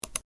鼠标单击声音相关的PPT合集_风云办公